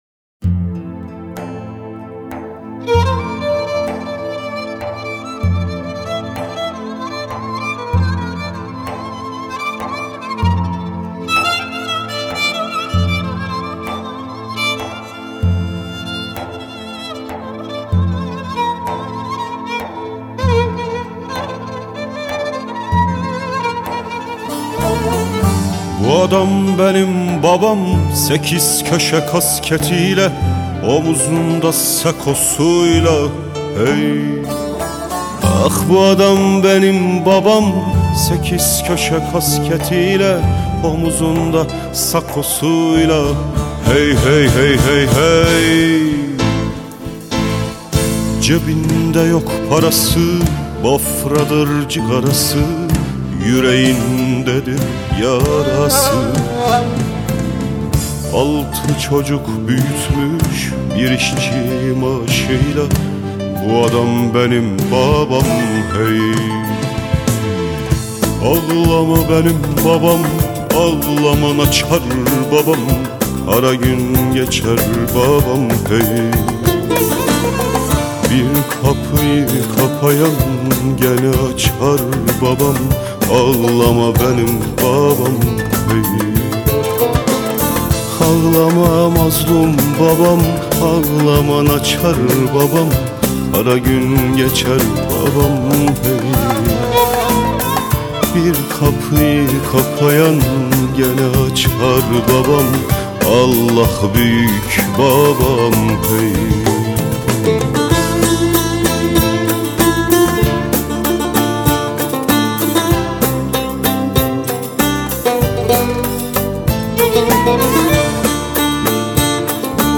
Anadolu' dan Türküler